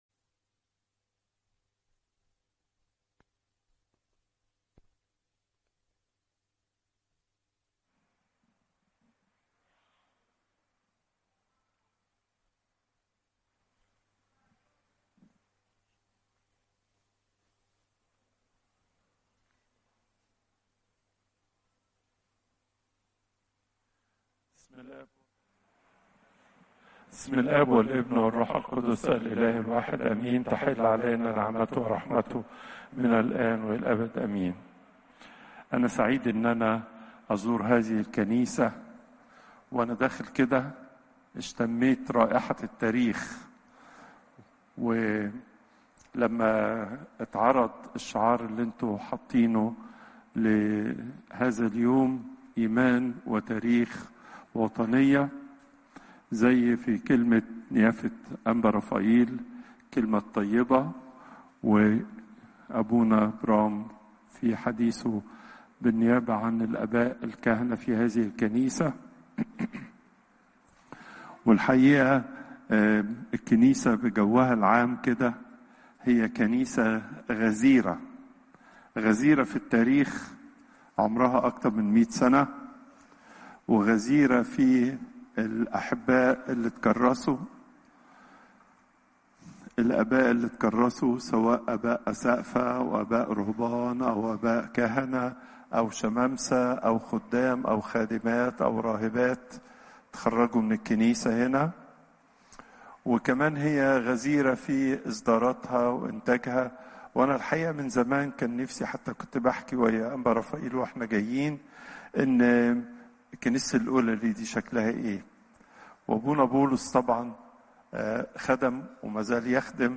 Popup Player تحميل الصوت البابا تواضروس الثانى الأربعاء، 24 سبتمبر 2025 43:43 المحاضرة الأسبوعية لقداسة البابا تواضروس الثاني الزيارات: 66